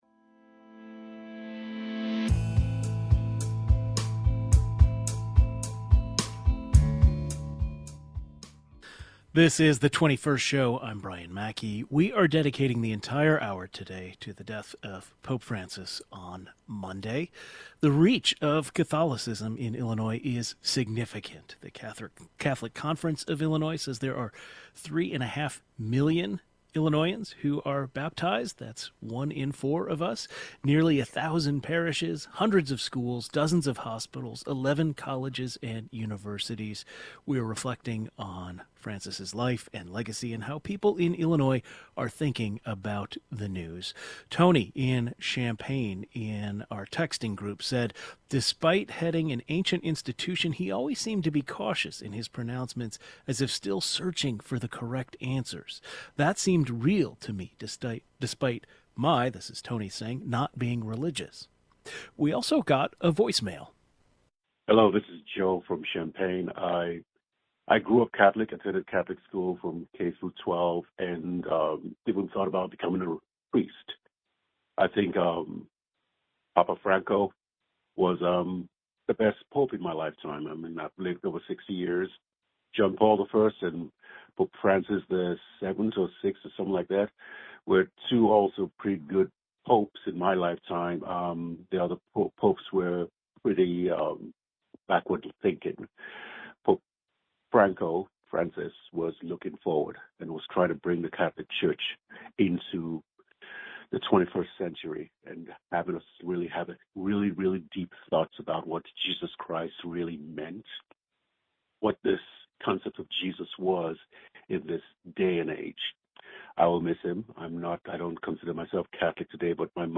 The top figure of the Catholic Church in the state as well as other leaders of the faith join the program today.